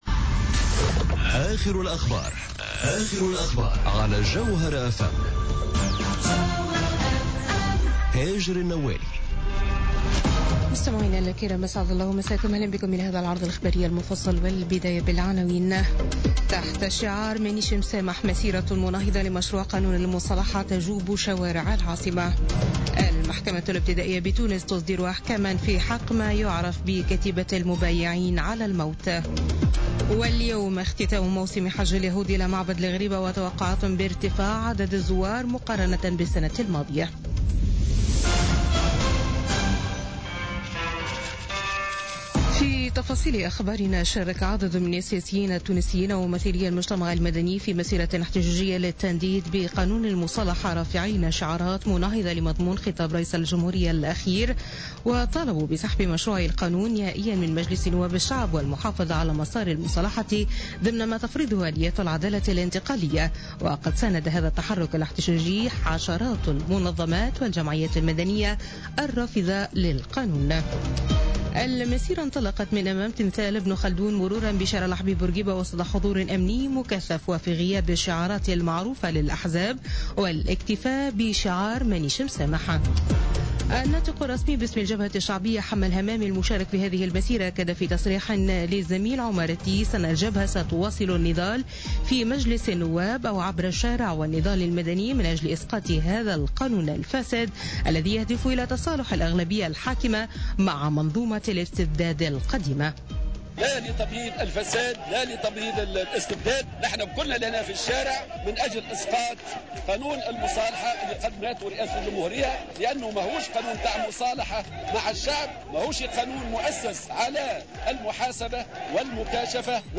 نشرة أخبار منتصف الليل ليوم الأحد 14 ماي 2017